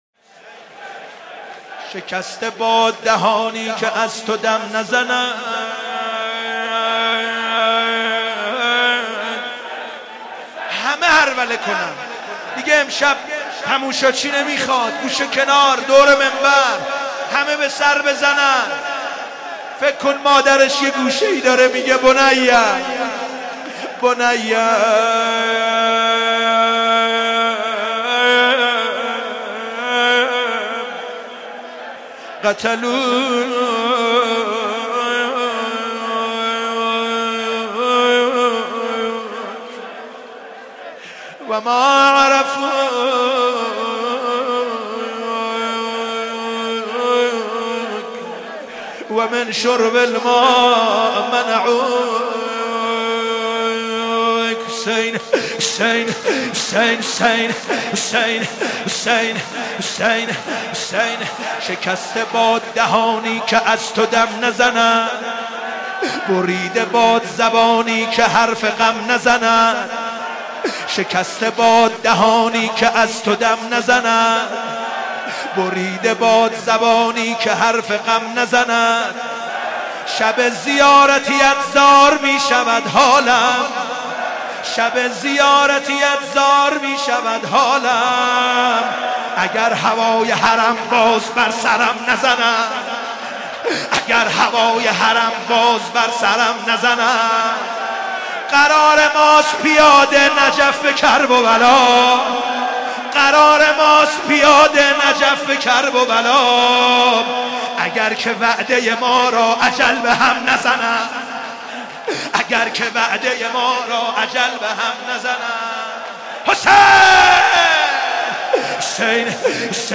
شب چهارم محرم97 تهران مسجد امیر